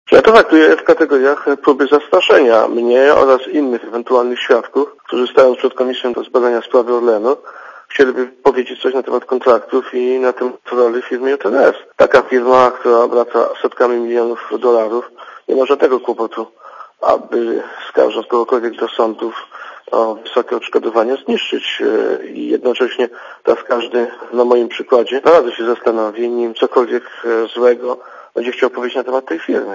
Posłuchaj komentarza Zbigniewa Siemiątkowskiego